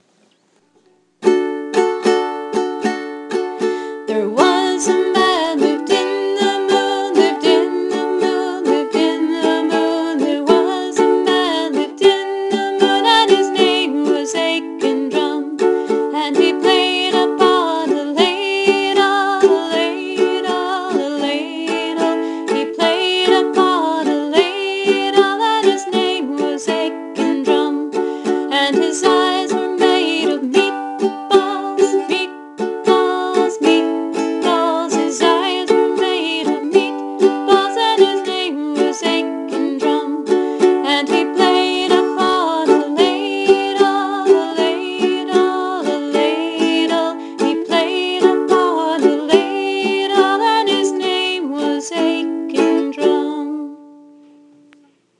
Aiken Drum: I had the kids suggest different foods to make the parts of Aiken Drum’s face.  Here are the lyrics with the uke chords in parentheses (If this key is too high, you can also play it in C with C, F, and G7).